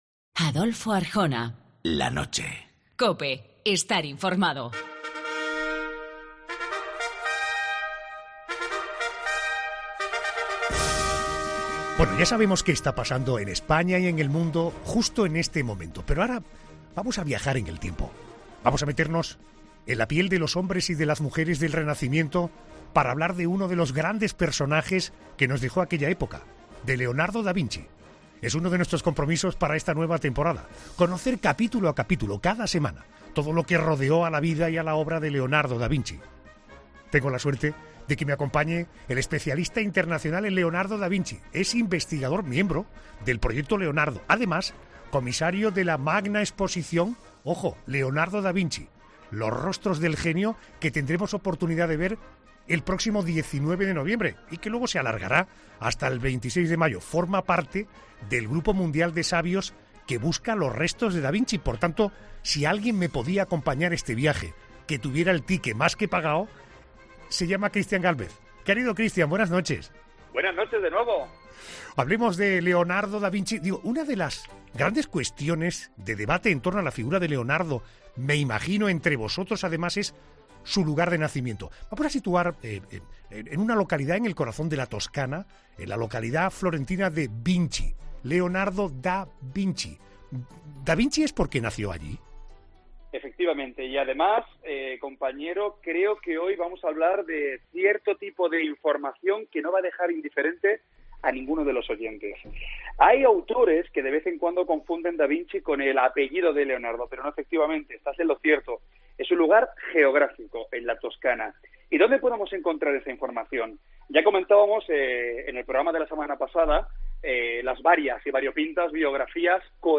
ESCUCHA LA ENTREVISTA COMPLETA EN 'LA NOCHE' Christian Gálvez es, por cierto, comisario de la exposición 'Leonardo da Vinci, los rostros del genio', una muestra internacional que se estrenará en España en 2019.